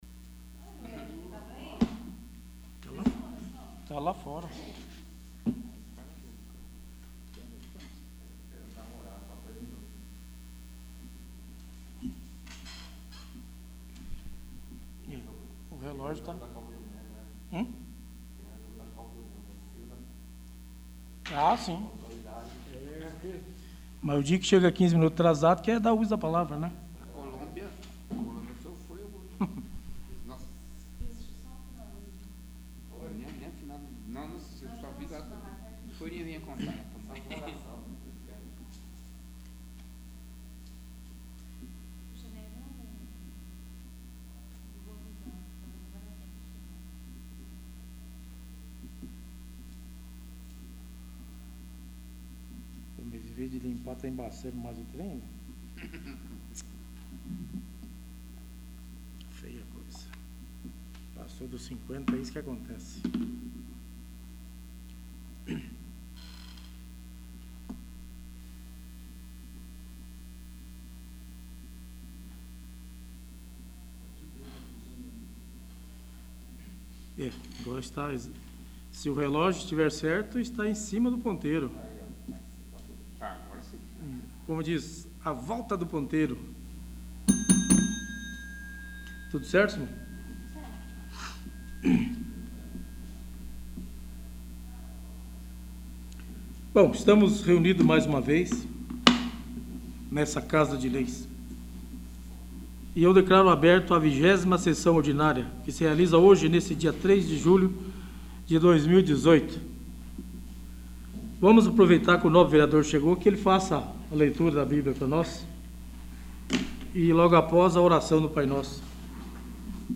20º. Sessão Ordinária